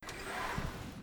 mechanic-doors.wav